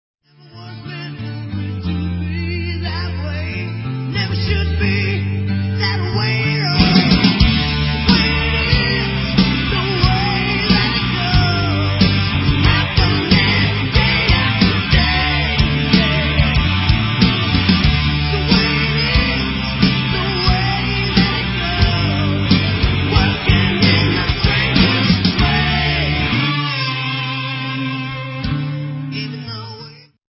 GUITAR
DRUMS
VOCALS
BASS